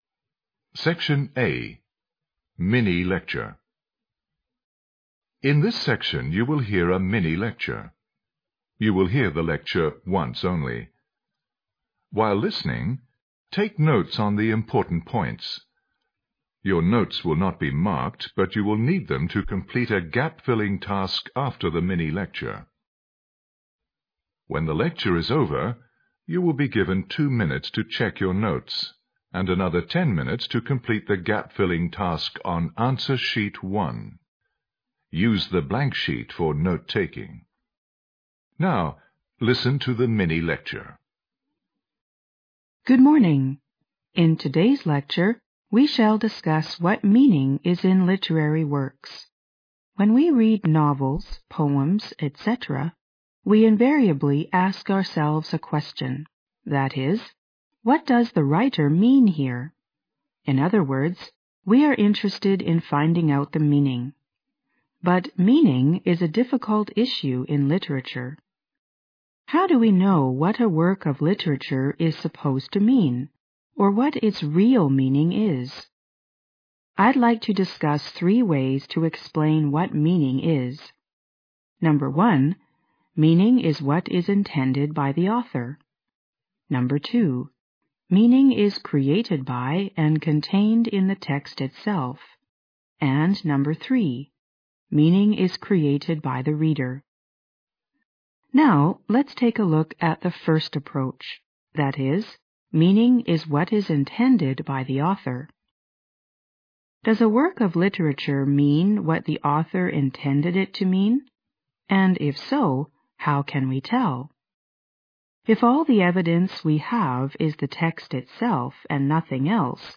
SECTION A MINI-LECTURE